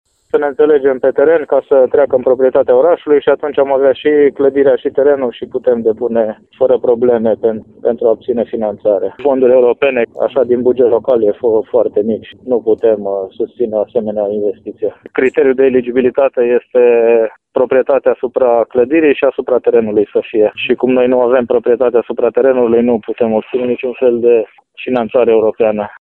Primarul din Herculane, Cristian Miclău, spune că proprietatea asupra clădirii și terenului reprezintă criterul de eligibilitate.